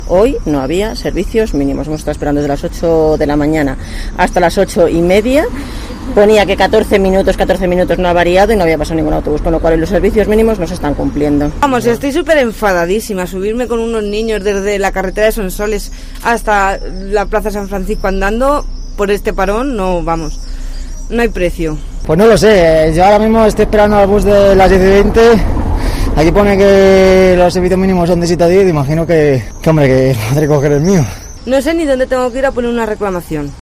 Tren-voces-autobus